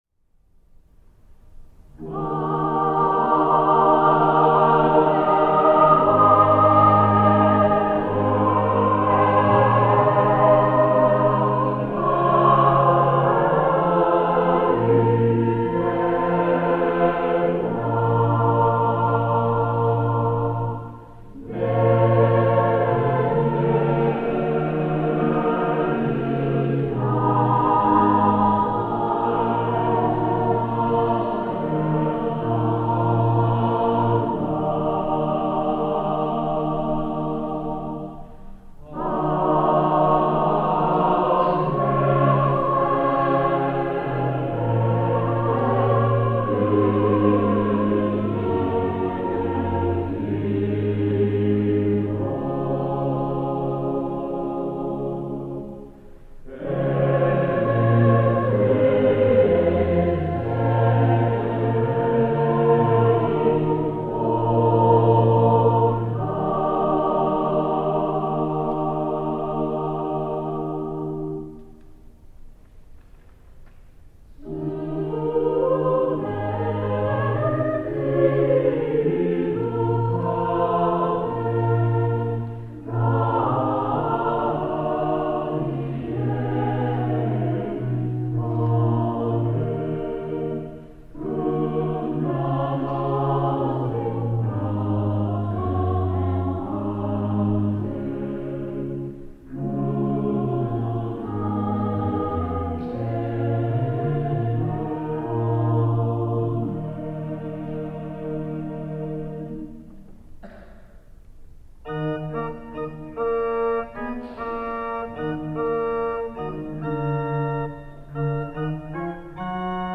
グレゴリオ聖歌の旋律を定旋律とし、冒頭の充実した響きの８声部の合唱に続き、同じ旋律を３拍子に変化させたものを４声部のホモフォニックな合唱で歌います。さらに、器楽によるリトルネッロをはさみながら、３人の独唱者が定旋律を歌い、最後にもう一度、冒頭の合唱が繰り返されるという多様な構成をもっています。